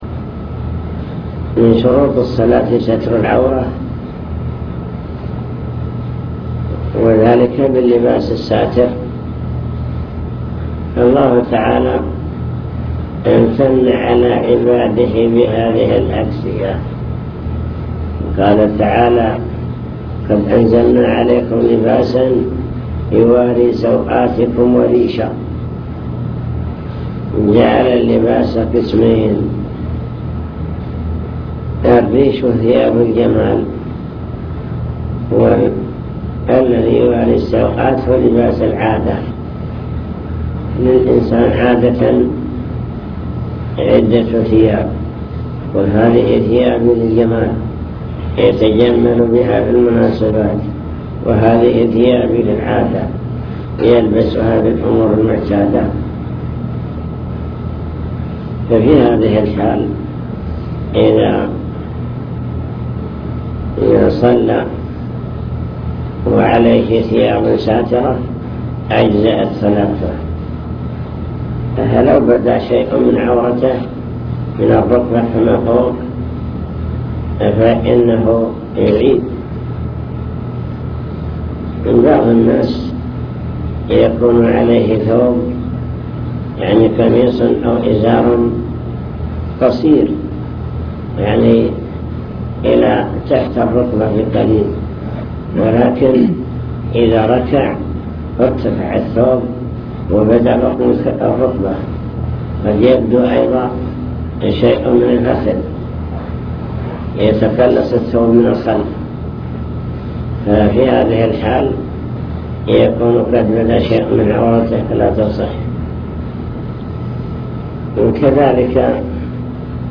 المكتبة الصوتية  تسجيلات - محاضرات ودروس  درس الفجر